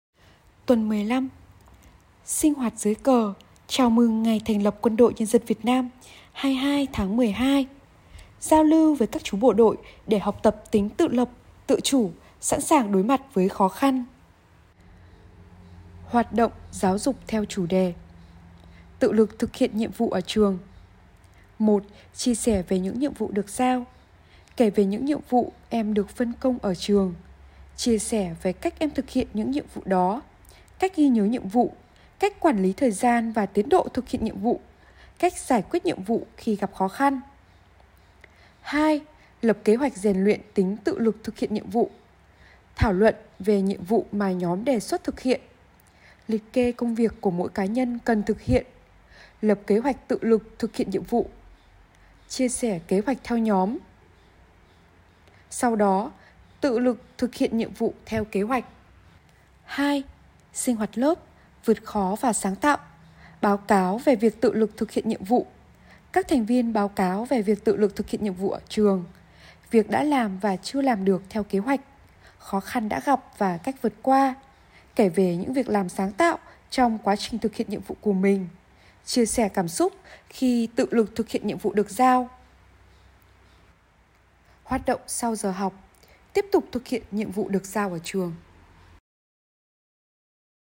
Sách nói | CHÀO MỪNG NGÀY THÀNH LẬP QUÂN ĐỘI NHÂN DÂN VIỆT NAM - HĐTN 4